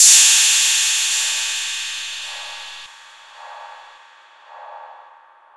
RIDEFX1   -R.wav